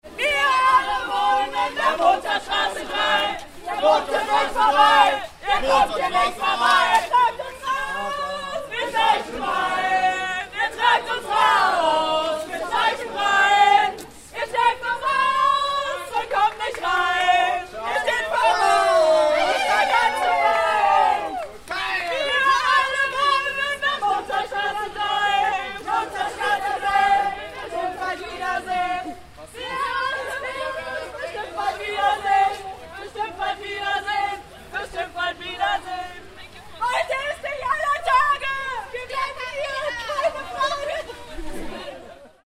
Hier noch ein paar musikalische Eindrücke aus der Mozartstraße: 2:10 2:26